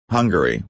(ˈhʌŋgəri)   Hungria Hungarian